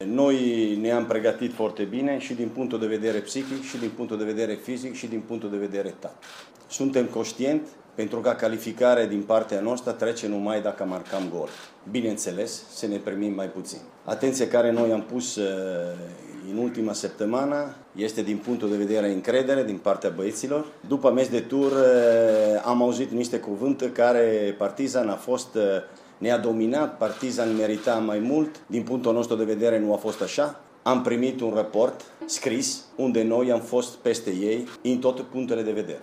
a spus la conferinta de presa că partida de la Belgrad este una extrem de importantă pentru ambele formaţii, nu doar pentru Steaua.